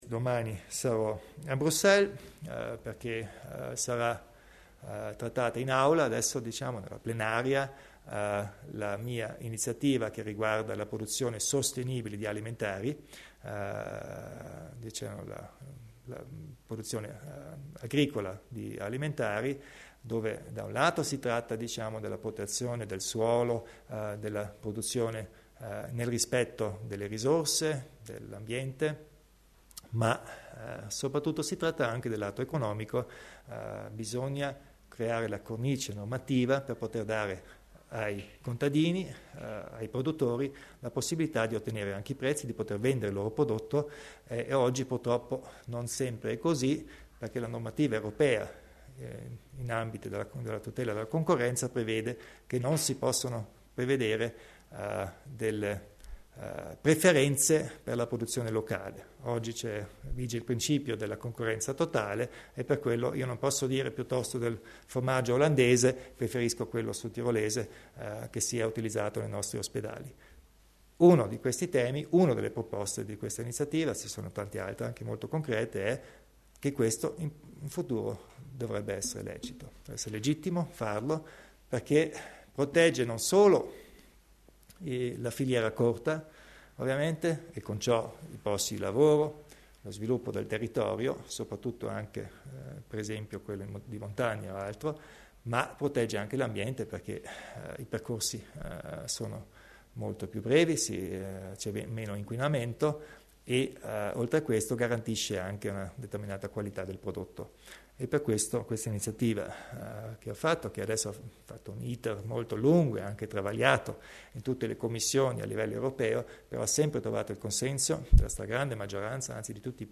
Il Presidente Kompatscher illustra la proposta a livello europeo per la tutela dei prodotti locali